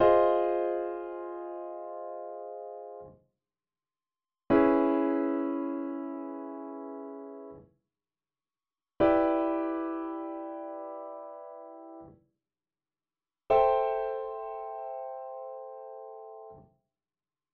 Now a few examples as block chords:
Minor Seventh Block Chords